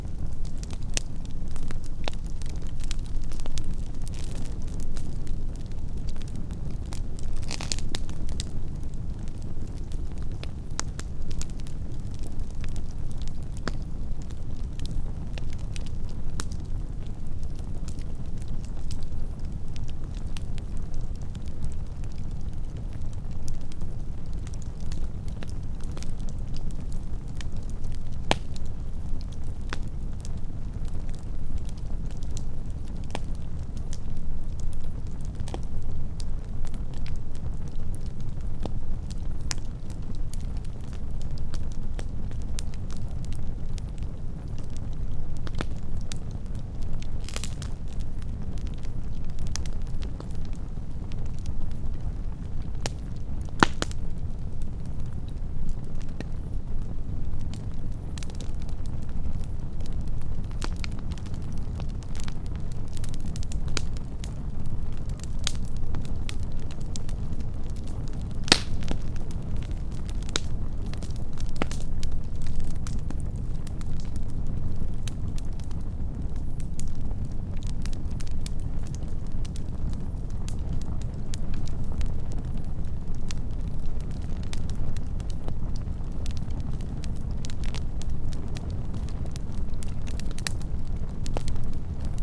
Fire-Burning.ogg